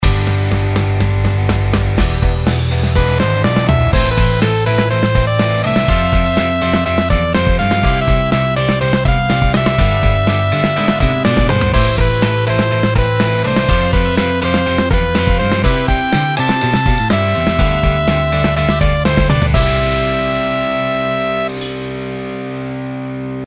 実際に我々音楽AI部が用意したAIでメロディを生成し、お題曲に追加した例が「02_生成曲.mp3」です！